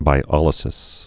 (bī-ŏlĭ-sĭs)